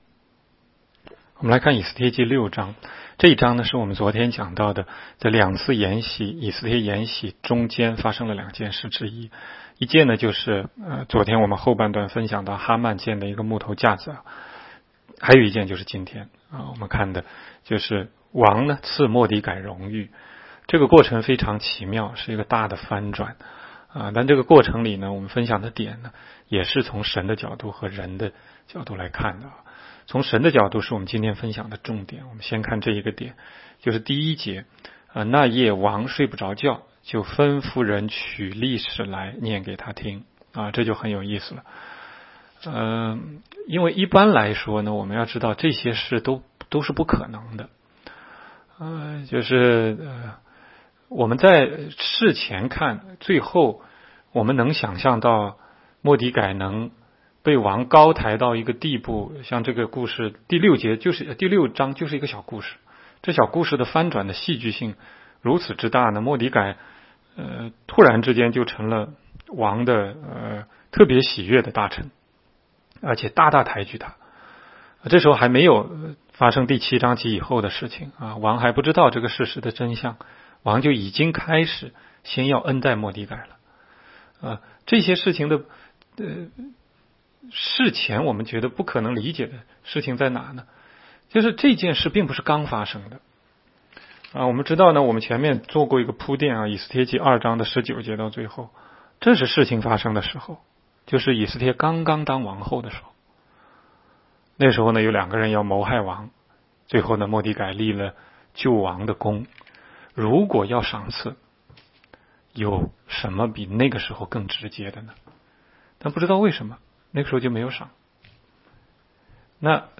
16街讲道录音 - 每日读经-《以斯帖记》6章